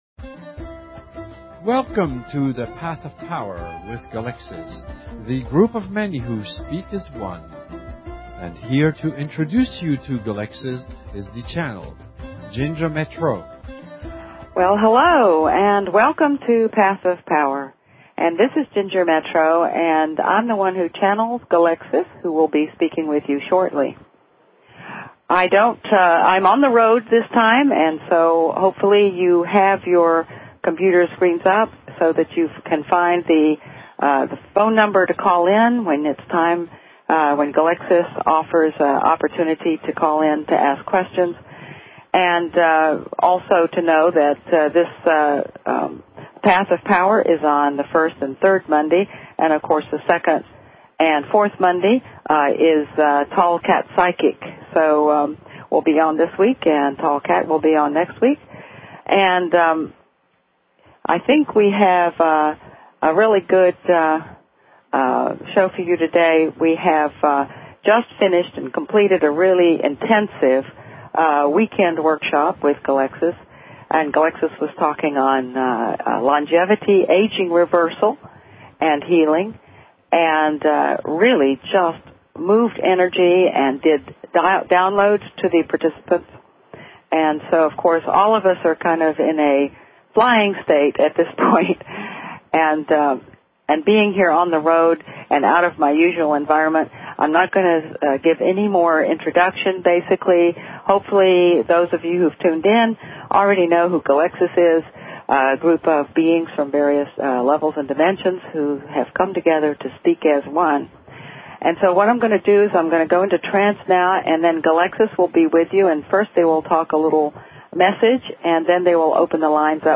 Talk Show Episode, Audio Podcast, Path_of_Power and Courtesy of BBS Radio on , show guests , about , categorized as